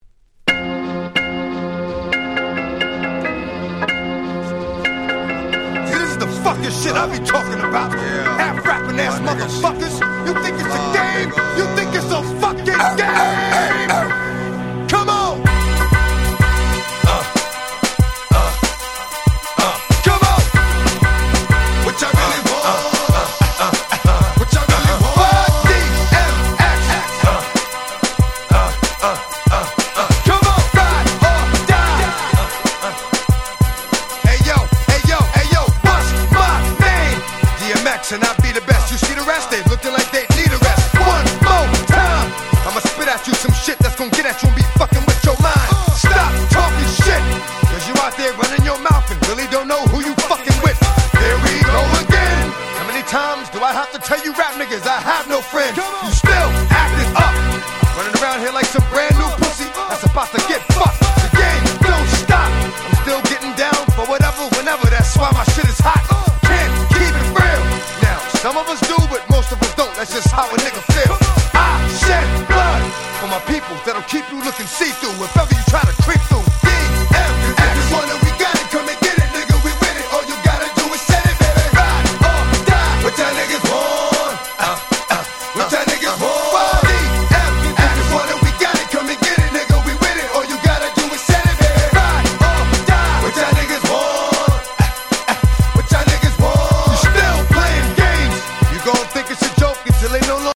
99' Smash Hit Hip Hop !!